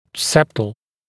[septl][сэптл]септальный, перегородочный